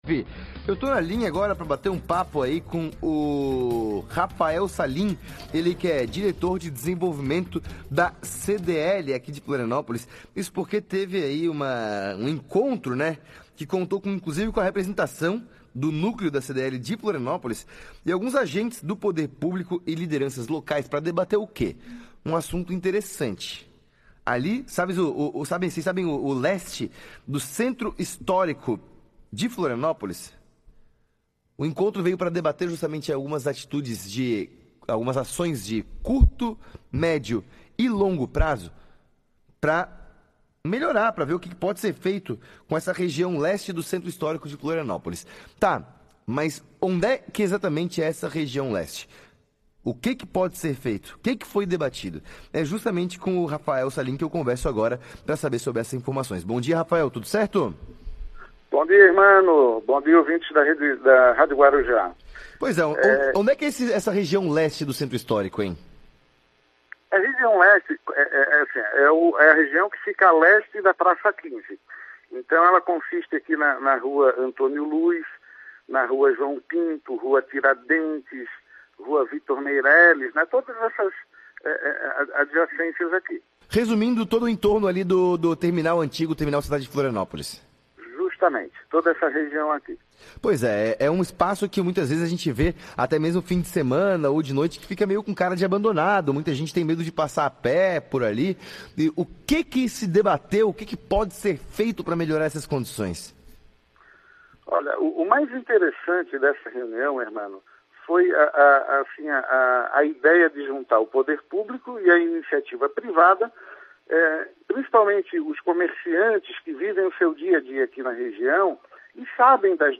CDL de Florianópolis - Rádio